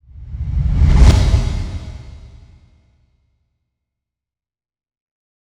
Long Woosh Sound Effect Free Download
Long Woosh